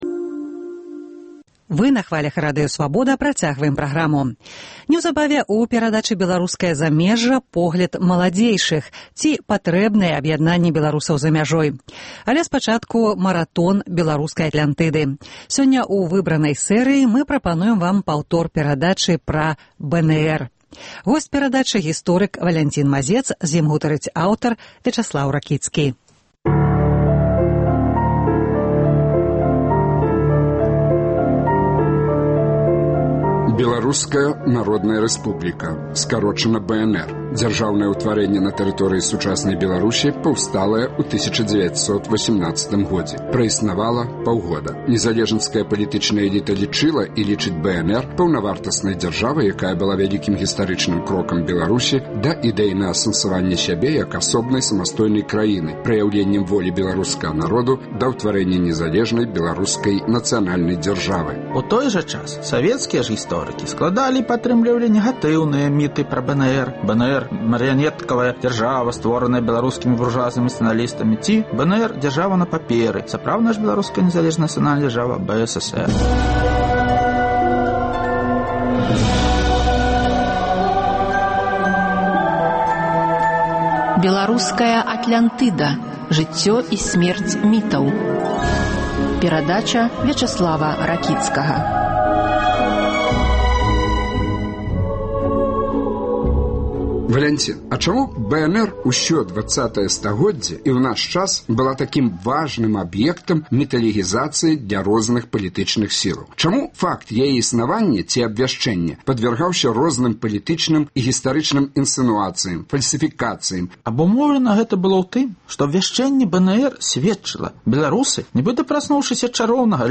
Маратон “Атлянтыдаў”, якія прагучалі на хвалях Свабоды ў сэрыі “Жыцьцё і сьмерць мітаў”.